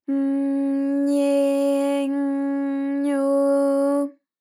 ALYS-DB-001-JPN - First Japanese UTAU vocal library of ALYS.
ny_J_nye_J_nyo.wav